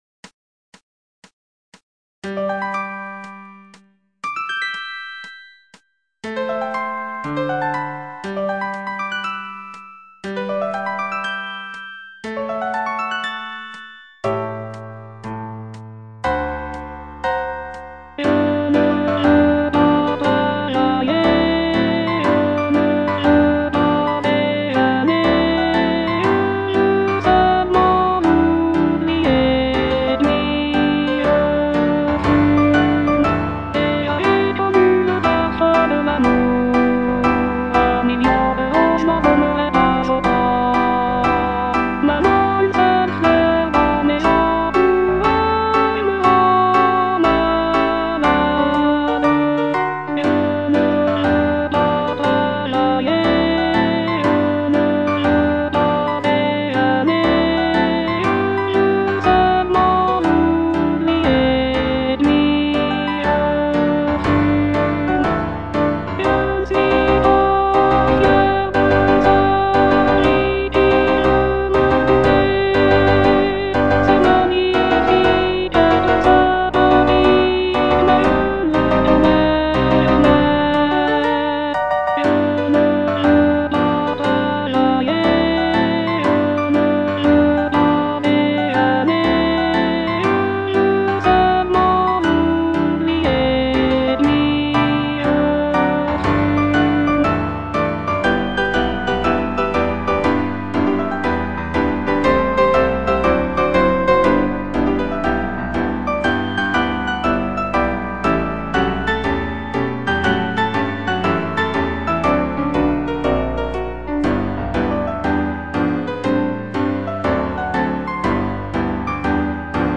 Alto I (Voice with metronome) Ads stop